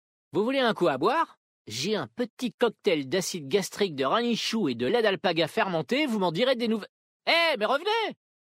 VF_Vulperin_Homme_Blague_Cocktail.mp3